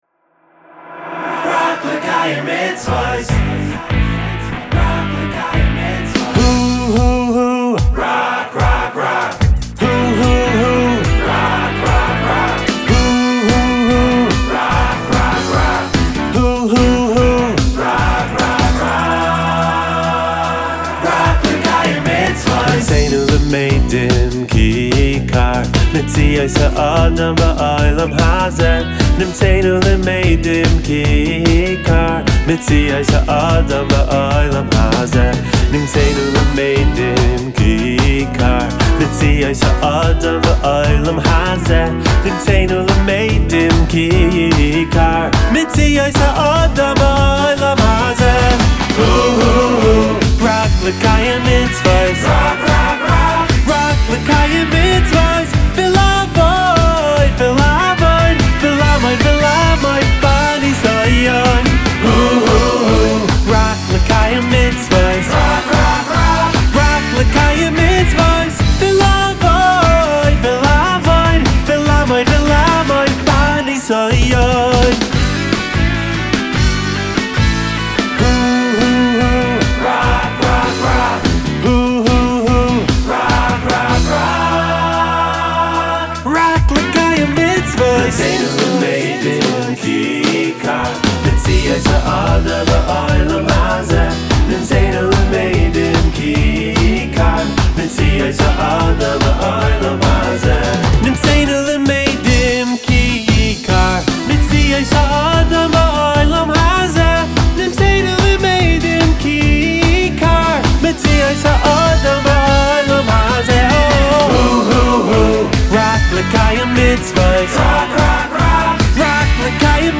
שירים חסידיים